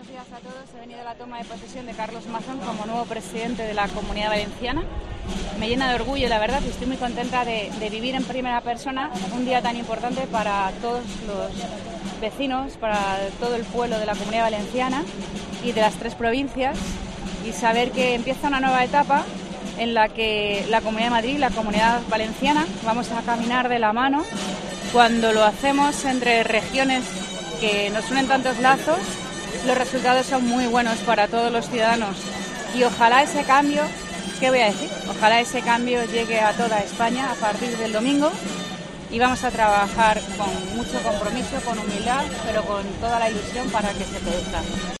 Declaraciones de Ayuso a la entrada de les Corts